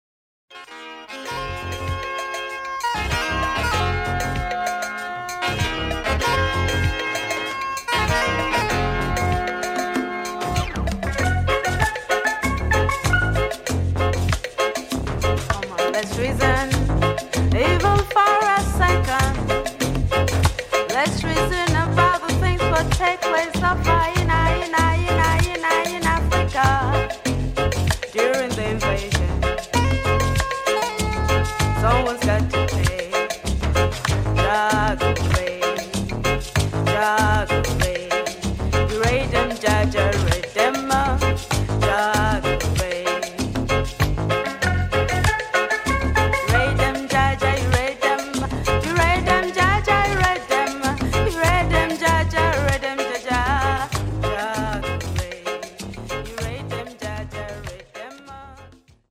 Nigerian reggae-influenced cuts from the late 80s